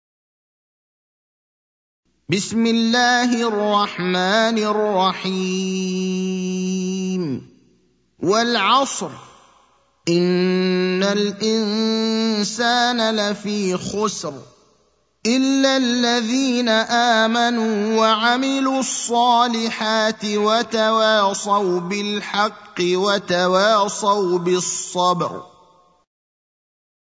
المكان: المسجد النبوي الشيخ: فضيلة الشيخ إبراهيم الأخضر فضيلة الشيخ إبراهيم الأخضر العصر (103)